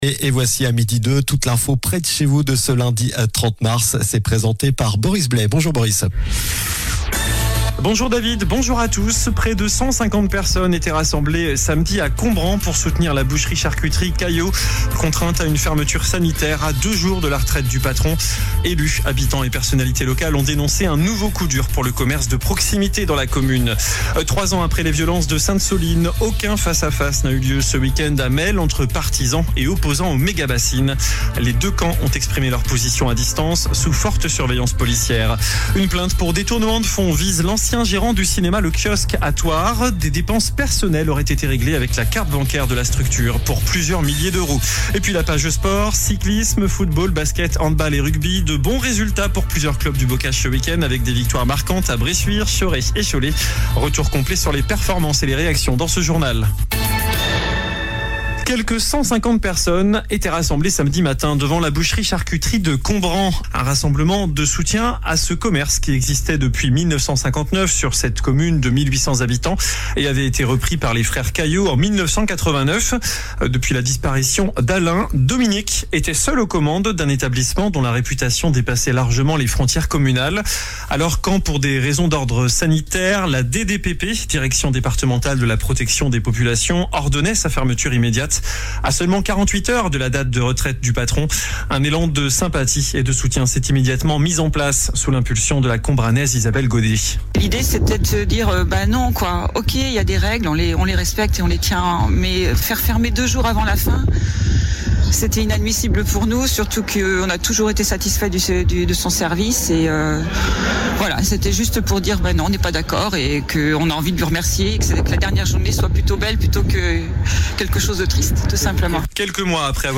Journal du lundi 30 mars (midi)